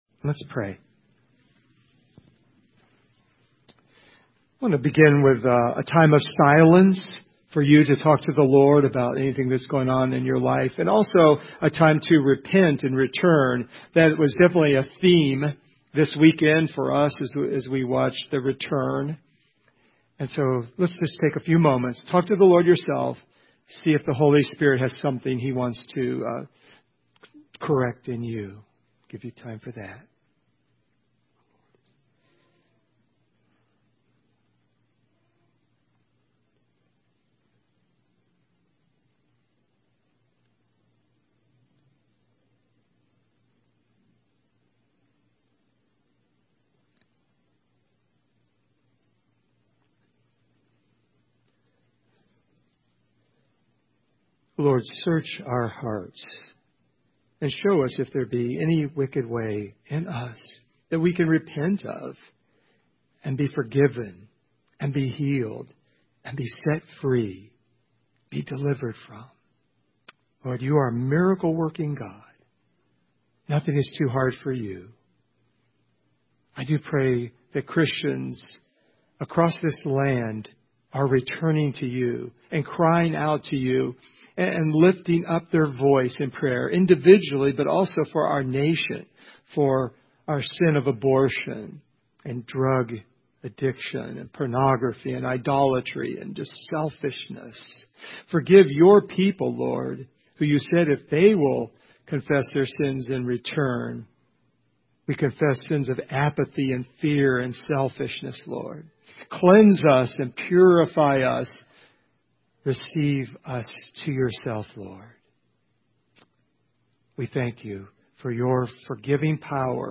In the last sermon in the series I talk about heaven in a Question & Answer format
Service Type: Sunday Morning